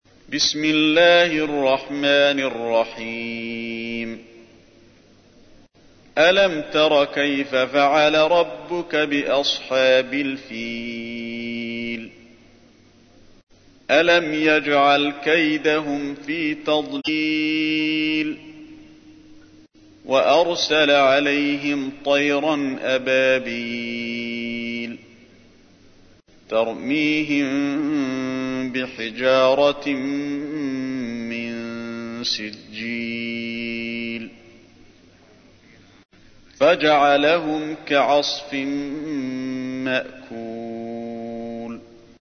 تحميل : 105. سورة الفيل / القارئ علي الحذيفي / القرآن الكريم / موقع يا حسين